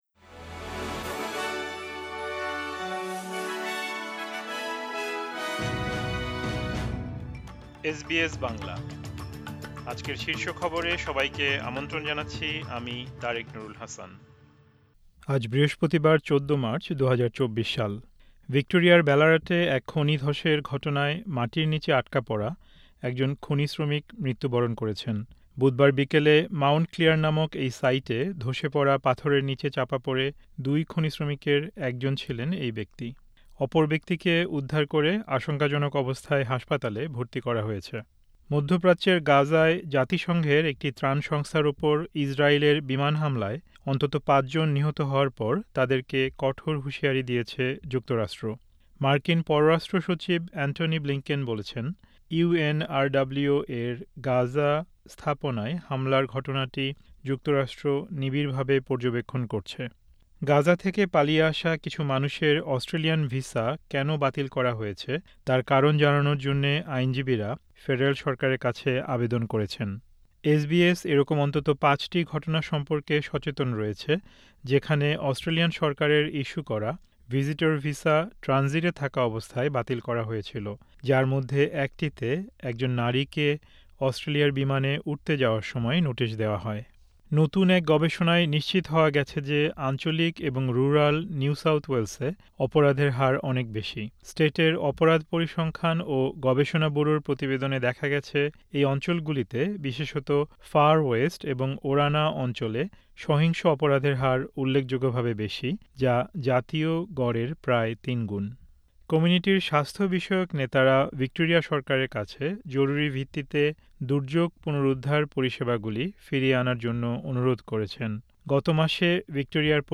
এসবিএস বাংলা শীর্ষ খবর: ১৪ মার্চ, ২০২৪